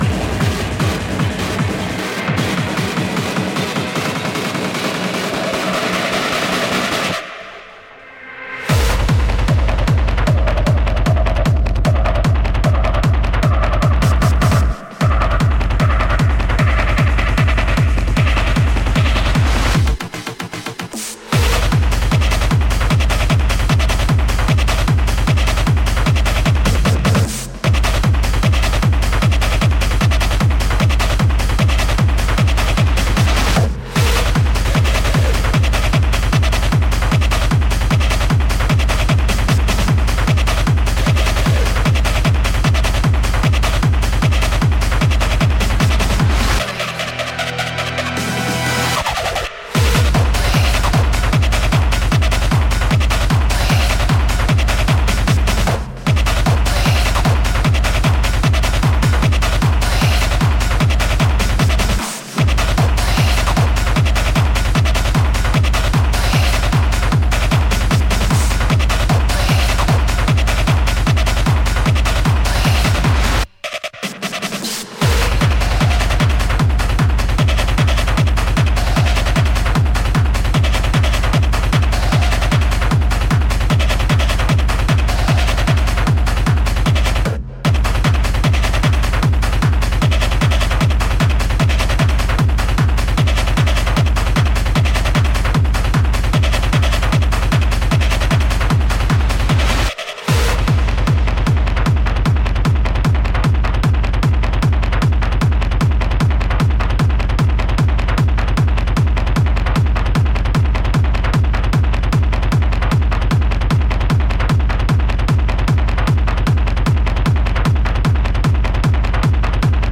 Techno, Hardcore